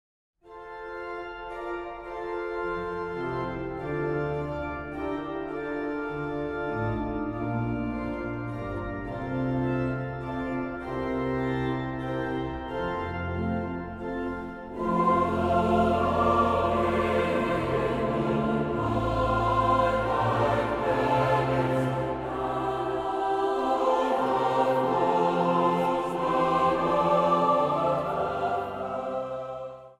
4 stemmen
Zang | Jongerenkoor